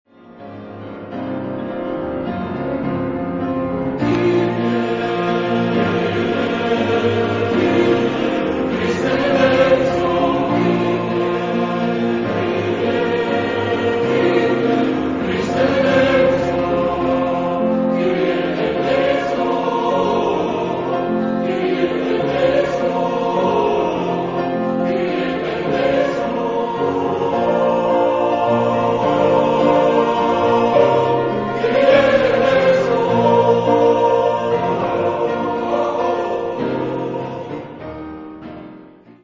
Mood of the piece: crisp ; sparkling ; powerful
Type of Choir: SATB  (4 mixed voices )
Instruments: Piano (1)
Tonality: free tonality